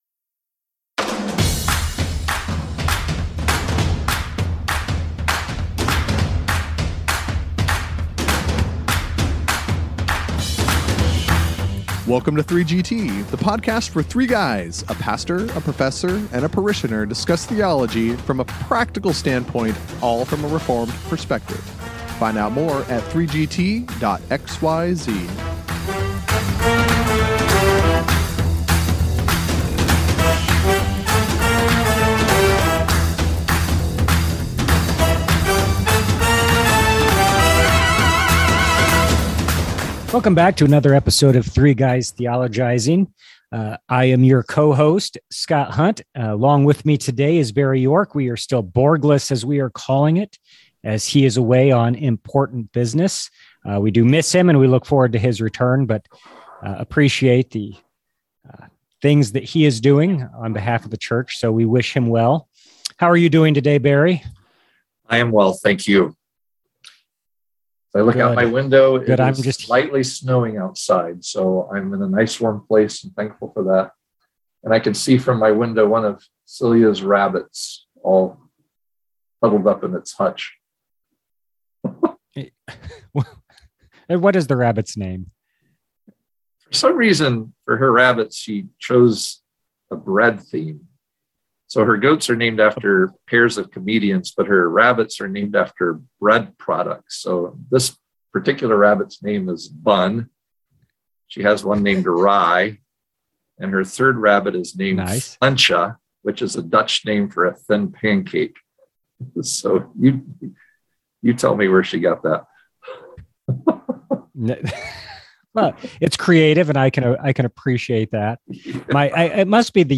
You’ll want to push the play button and join in this lively discussion on 3GT!